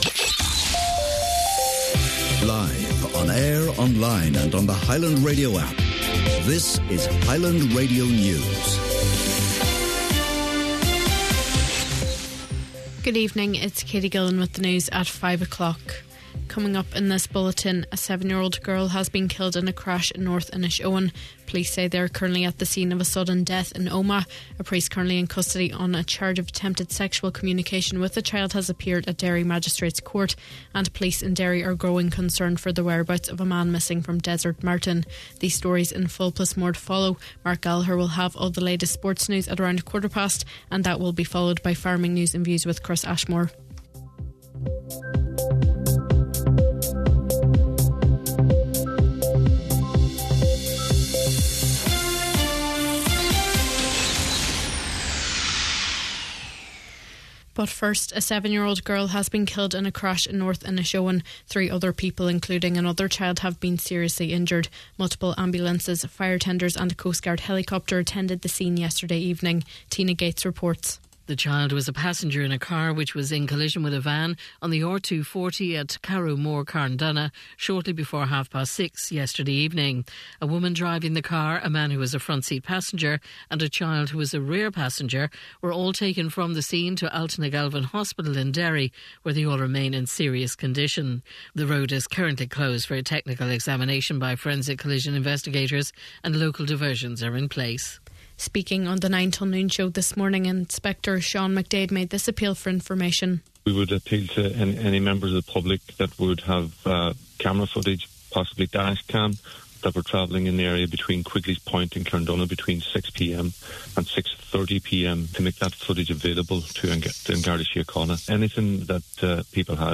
Main Evening News, Sport, Farming News and Obituaries – Thursday July 3rd